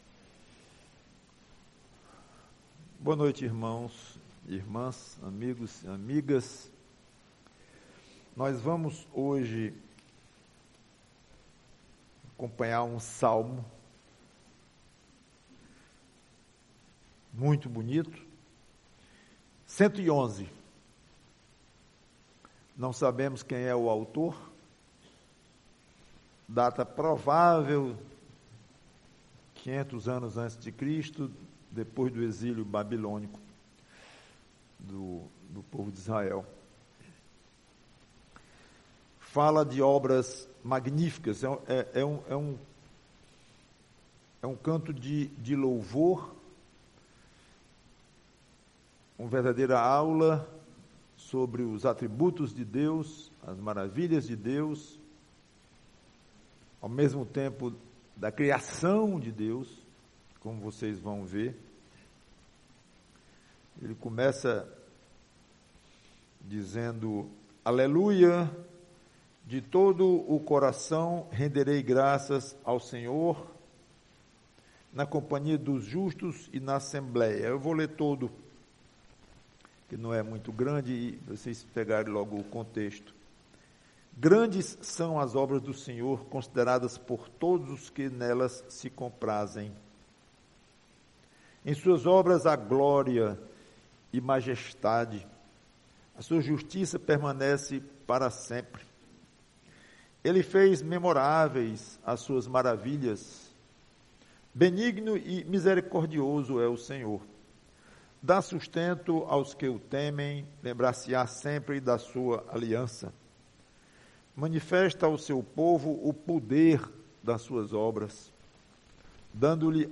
PREGAÇÃO